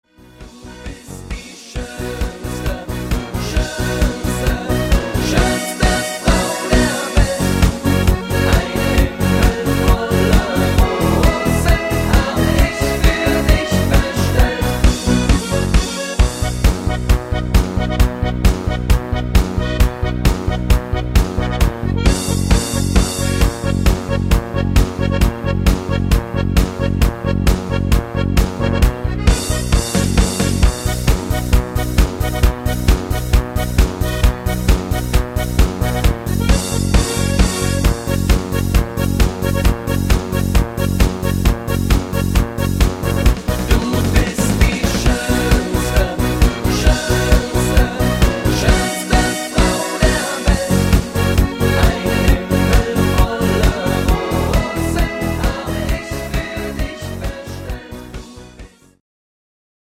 Party Hit